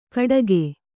♪ gaḍage